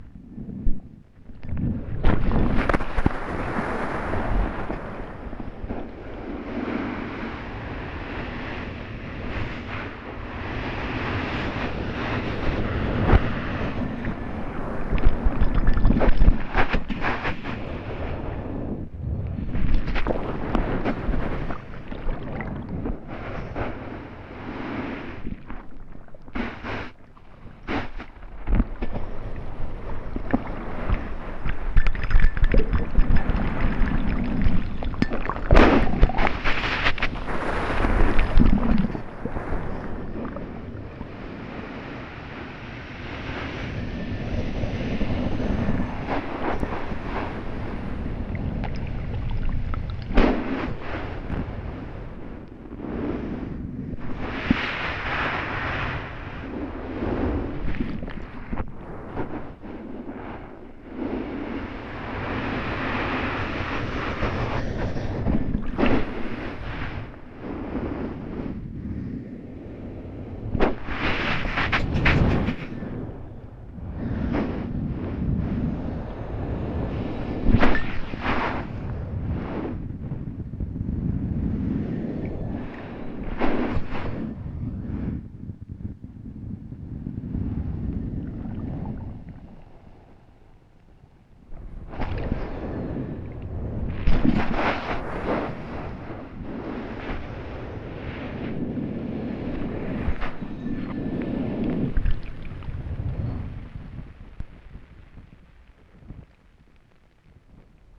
Den Haag Seaside (Recorded through Hydrophone and Hydroglass, 2021)